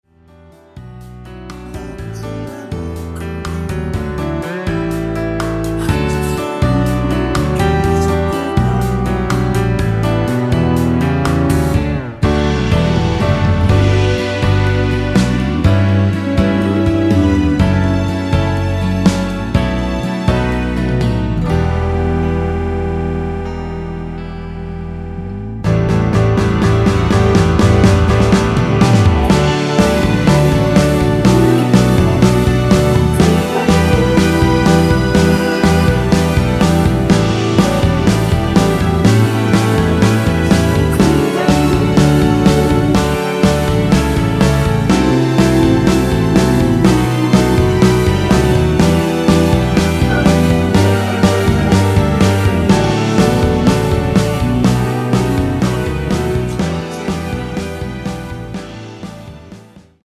원키에서(-1)내린 코러스 포함된 MR 입니다.
◈ 곡명 옆 (-1)은 반음 내림, (+1)은 반음 올림 입니다.
앞부분30초, 뒷부분30초씩 편집해서 올려 드리고 있습니다.